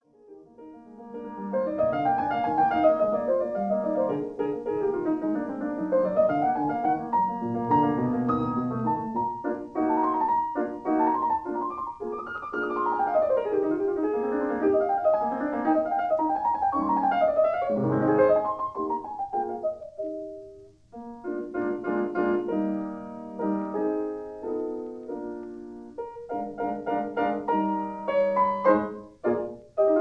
Mono recording made 15-16 September
New York City